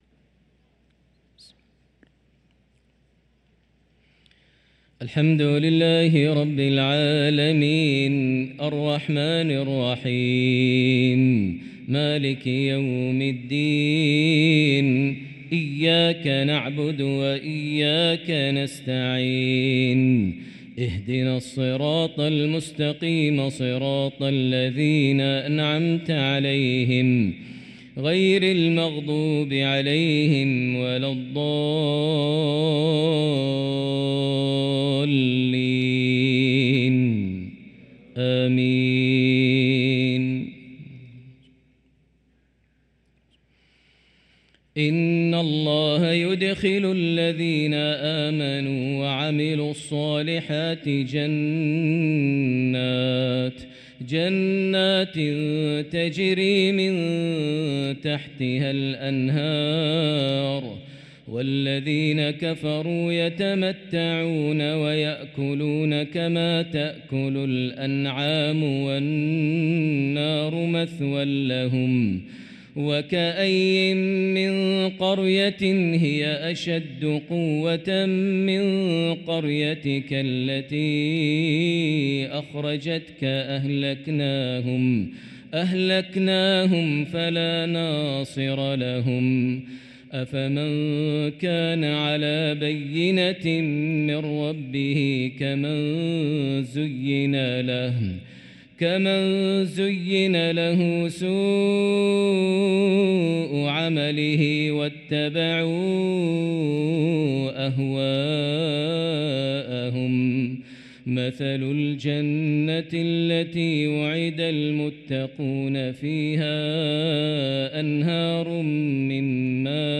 صلاة العشاء للقارئ ماهر المعيقلي 18 ربيع الآخر 1445 هـ
تِلَاوَات الْحَرَمَيْن .